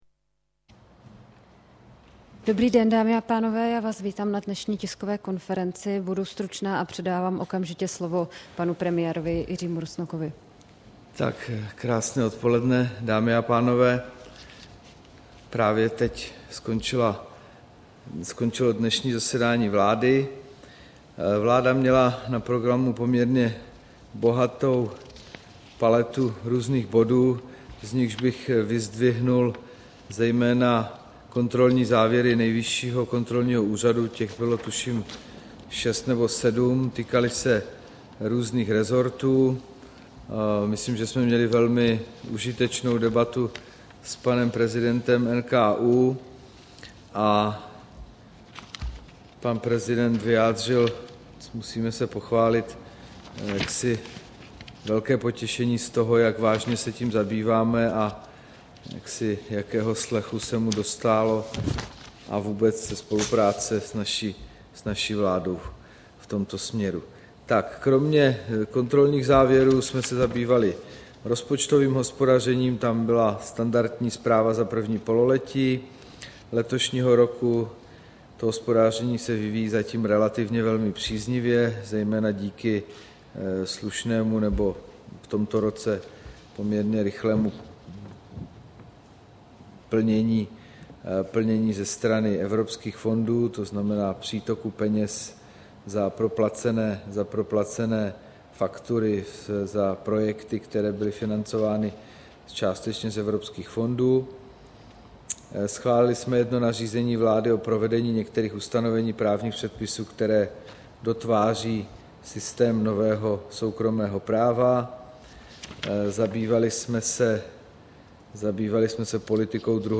Tisková konference po jednání vlády, 16. října 2013